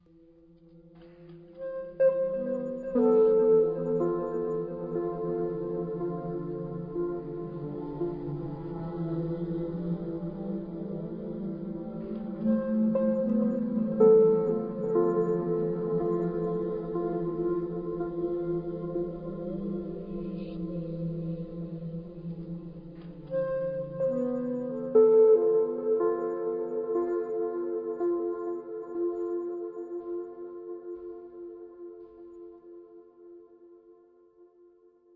标签： 电子 氛围 合成器 处理 音乐 介绍 声音 黑暗 电影 音景 科幻 噪音 大气
声道立体声